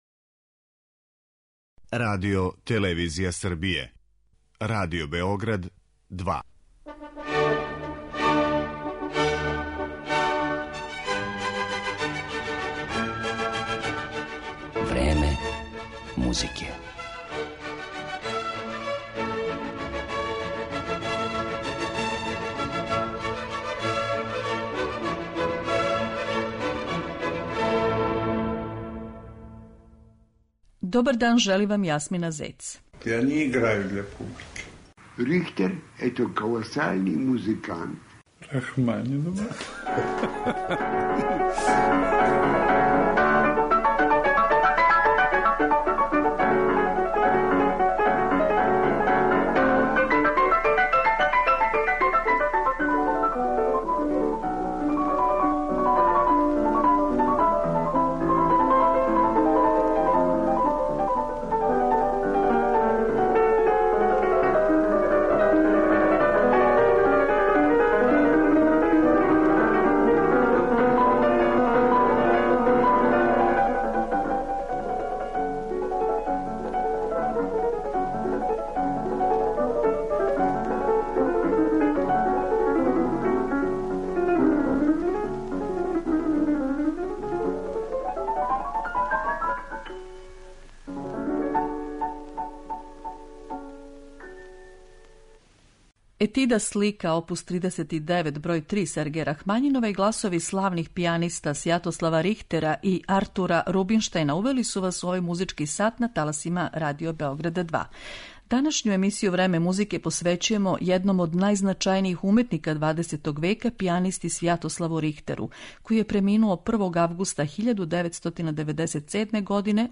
Представићемо га композицијама Бетовена, Рахмањинова, Чајковског и Баха, која је често изводио, а поред Свјатослава Рихтера, у емисији ће говорити Артур Рубинштајн и Глен Гулд.